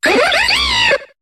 Cri de Rhinolove dans Pokémon HOME.